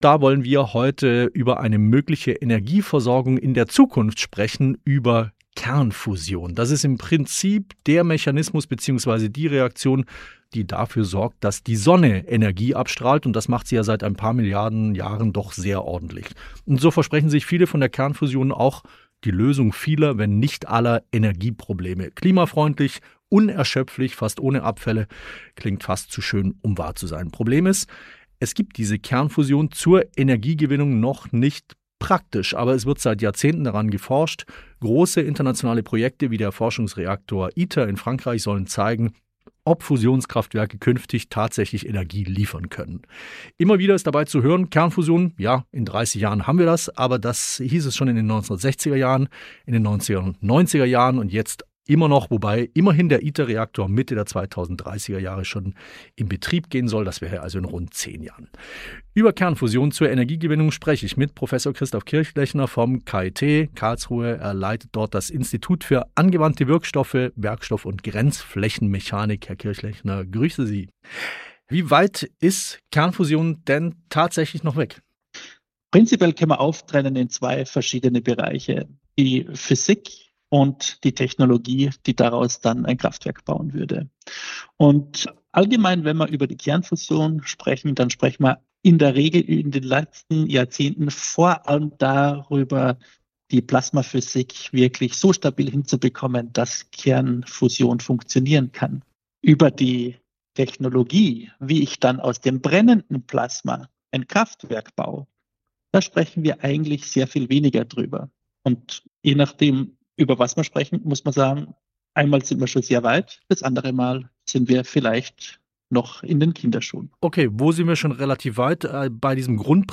Interview mit